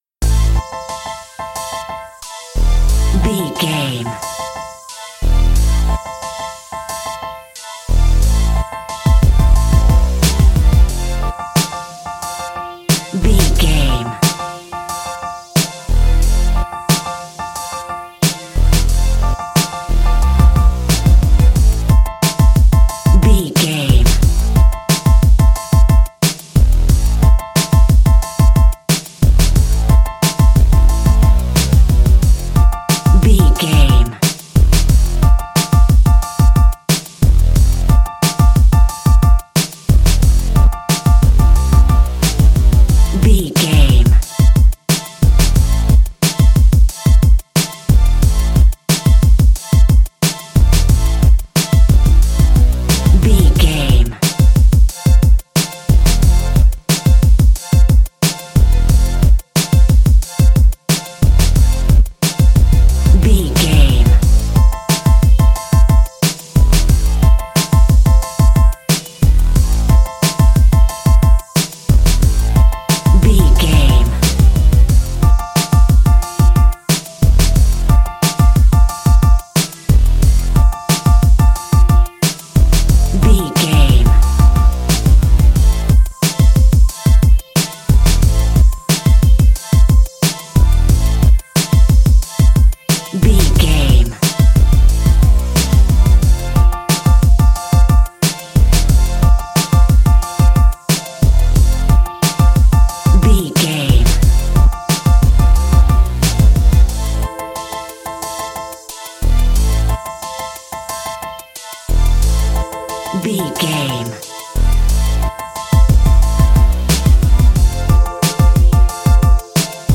Aeolian/Minor
synthesiser
drum machine
strings